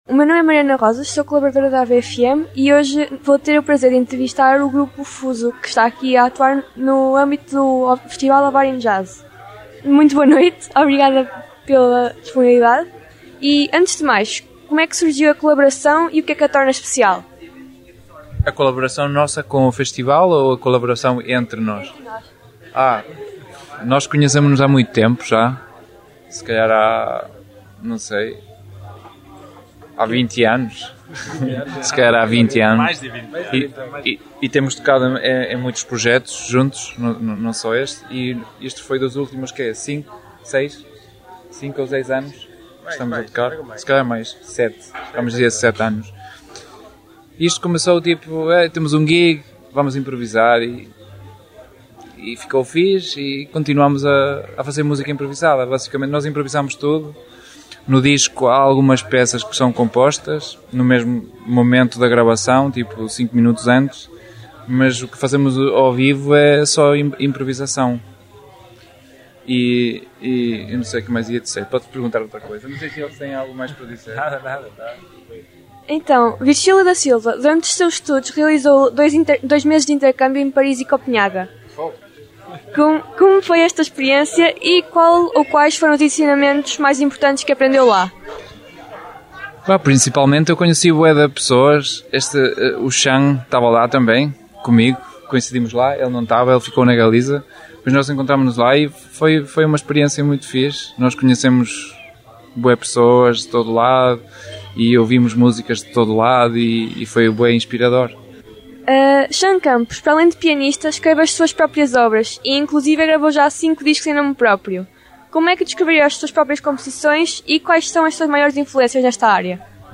Ouça a entrevista realizada aos membros do Fuzzo, no âmbito do concerto no Ovar em Jazz: